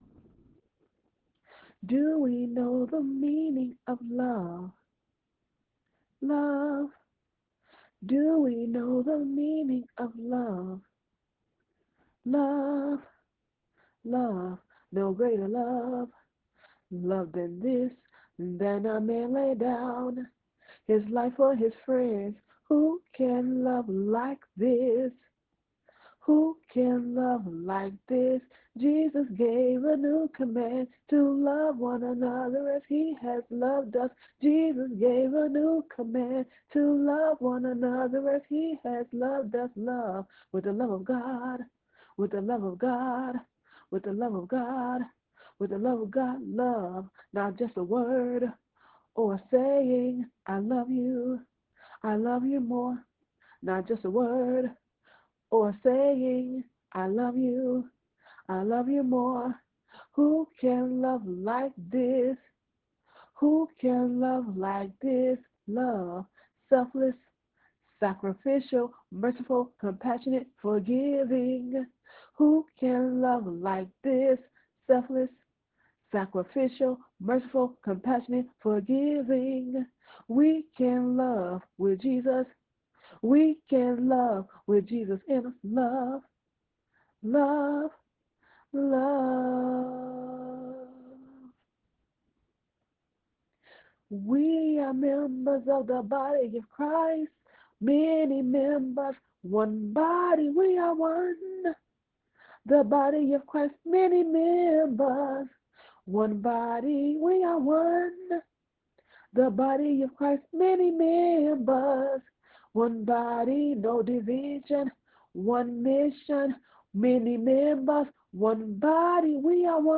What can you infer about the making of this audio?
The following Messages was not recorded live.